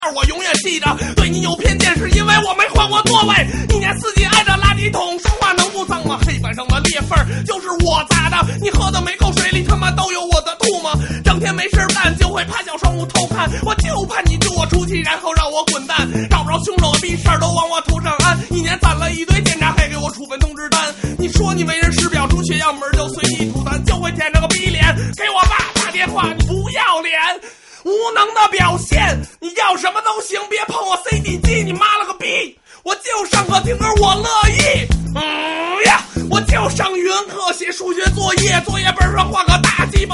Китайский рэп